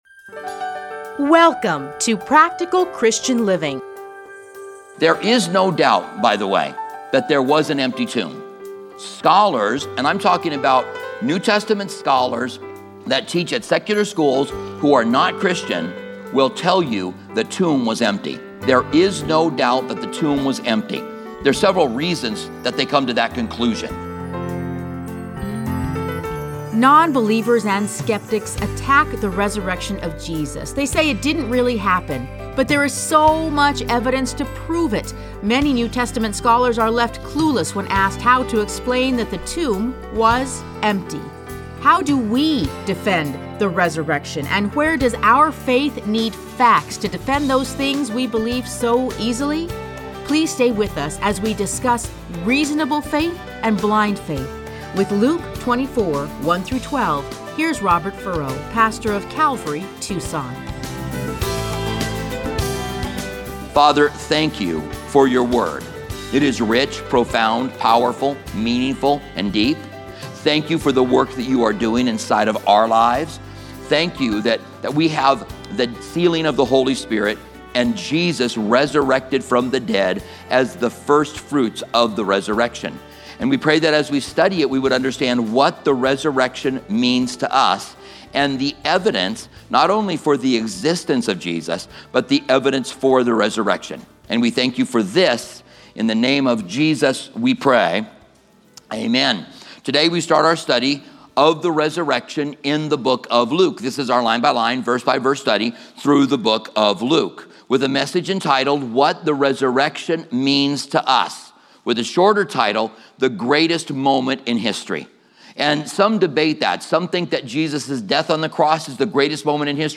Listen to a teaching from Luke 24:1-12.